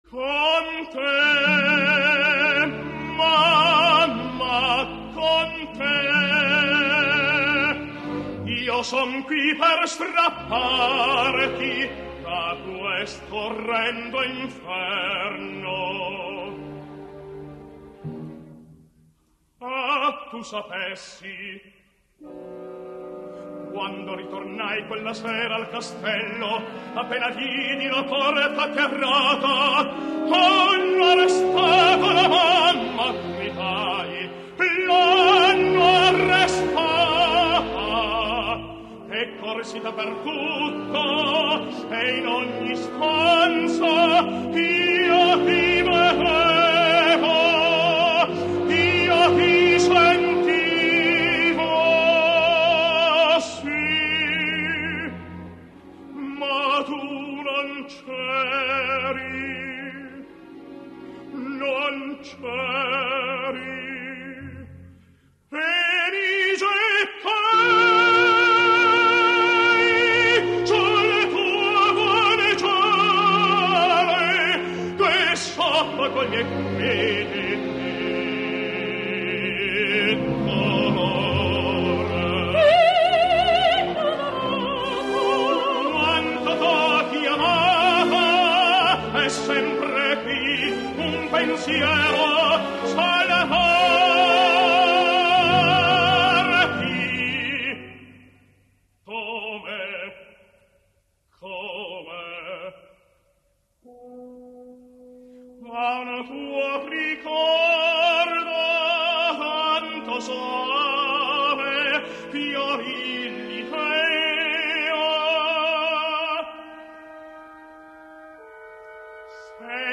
Revolutionsoper — {historischer Verismo}
Il principino (& la principessa)
La principessa rispettivamente La mamma [Alt]